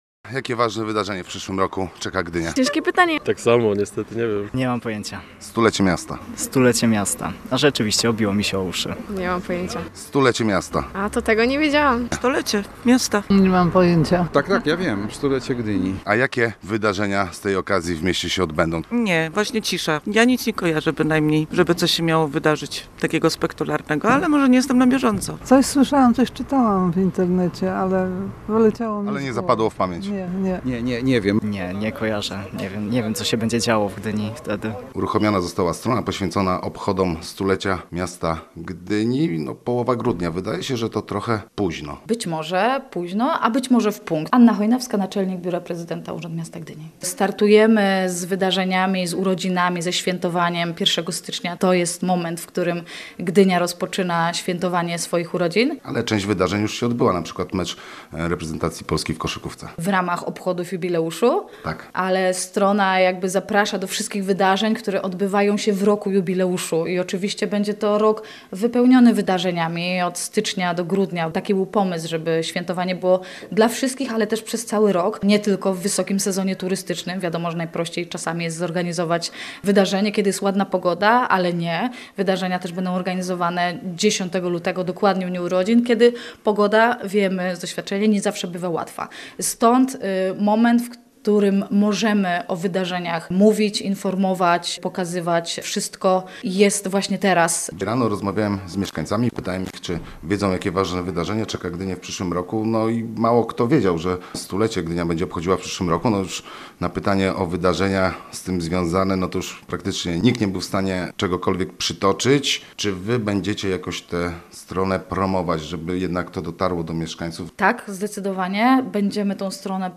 Więcej o stronie poświęconej obchodom 100-lecia Gdyni, ale też o problemach z informowaniem mieszkańców o planowanych wydarzeniach, w materiale naszego reportera.